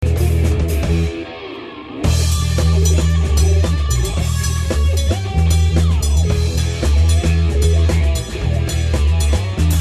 Soundbeispiele von Live Aufnahmen
1. Über Stereo Pult:
Bandprobenaufnahmen mit zwei Mikros die an ein kleines Behringer Pult angeschlossen waren. Aus dem Pult ging's in den "Line In" des IHP.
stereomikros2.mp3